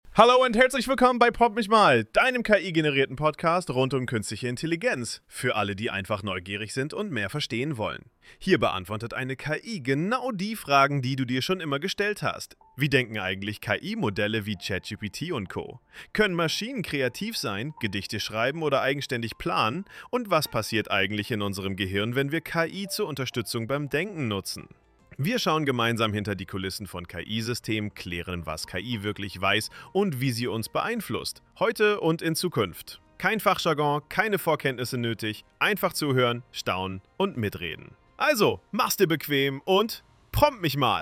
KI-generierten Podcast rund um künstliche Intelligenz – für alle,
Hier beantwortet eine KI genau die Fragen, die du dir schon immer